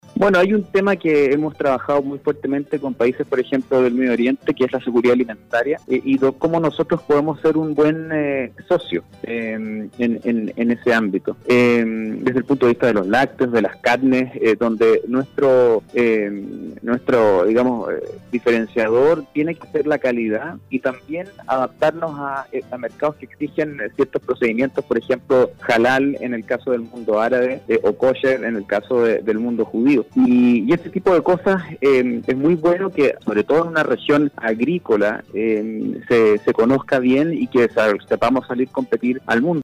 En entrevista con “Campo al Día” de Radio SAGO , Rodrigo Yáñez, Subsecretario de Relaciones Económicas Internacionales de Chile, señaló que debemos dar una mirada al lado, que no se produzca este efecto “cordillera”, no mirarnos el ombligo con realidades sin sustento.